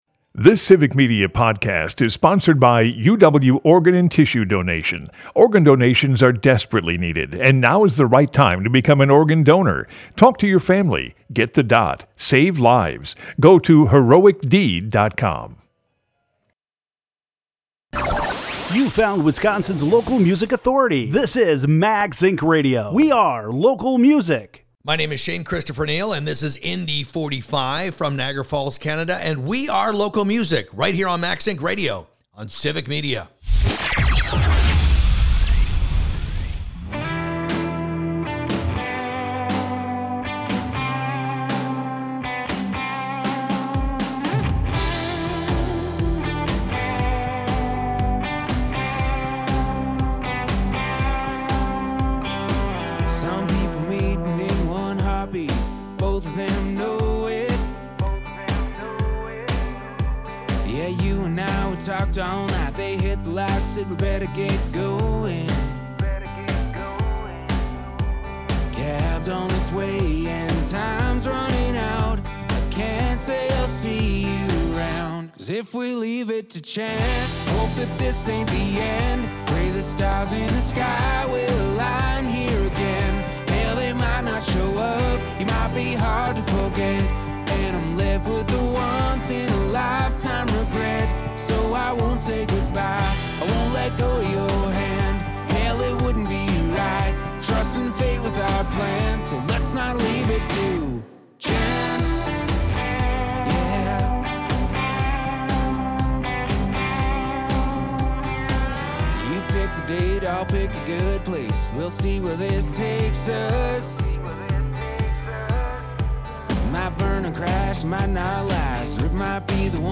Max Ink Radio is Wisconsin’s local music radio show originating in Madison and featuring music, interviews, performances, and premieres of Wisconsin artists.